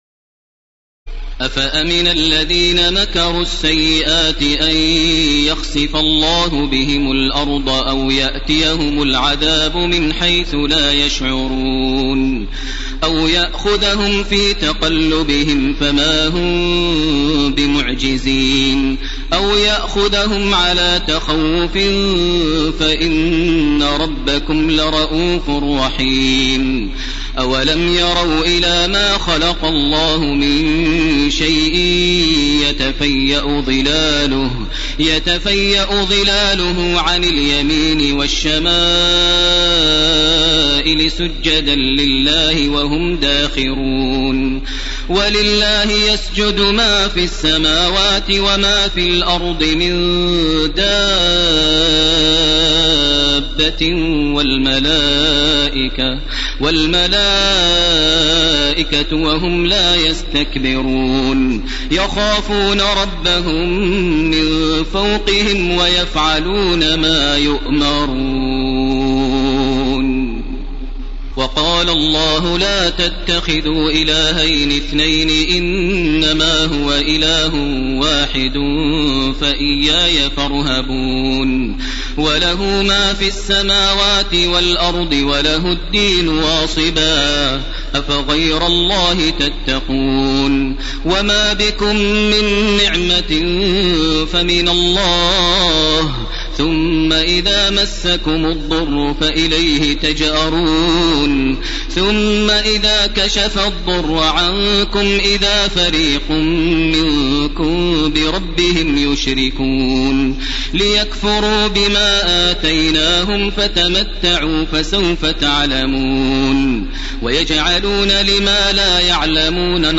تراويح الليلة الرابعة عشر رمضان 1431هـ من سورة النحل (45-128) Taraweeh 14 st night Ramadan 1431H from Surah An-Nahl > تراويح الحرم المكي عام 1431 🕋 > التراويح - تلاوات الحرمين